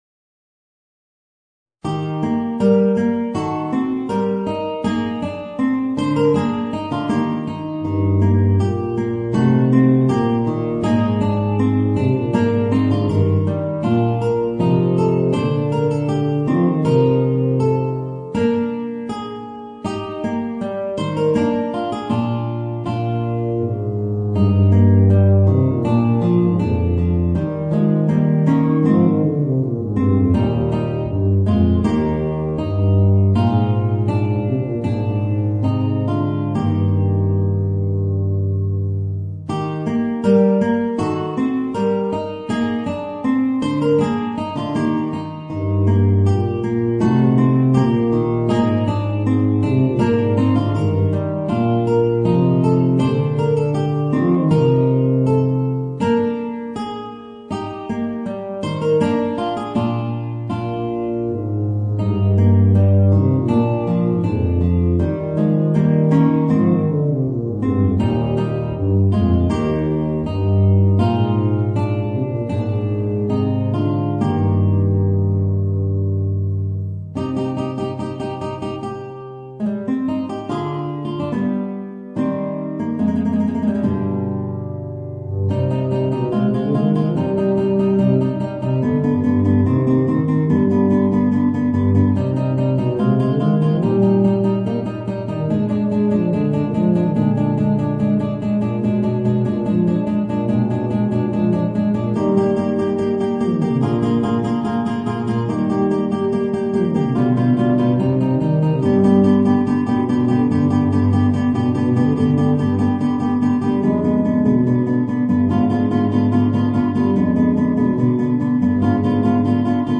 Voicing: Guitar and Eb Bass